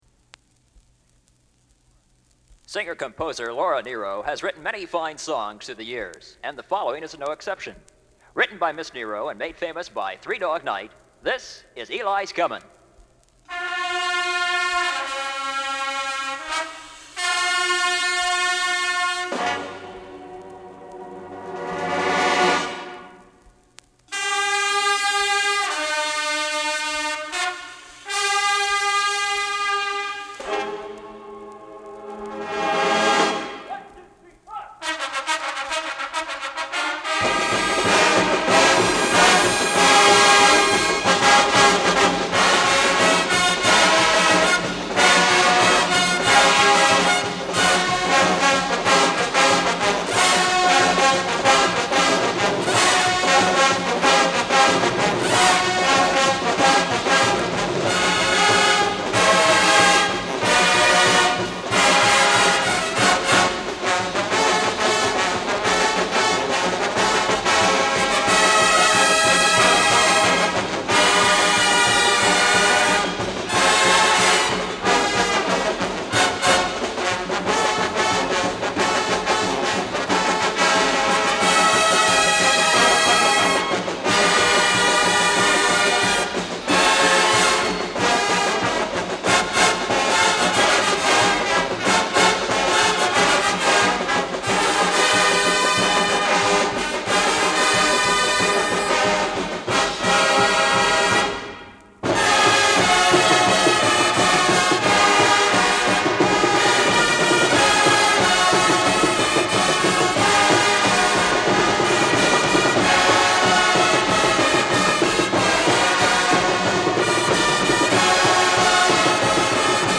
Marching Tornados Band
1973 Marching Tornados Band LP Recording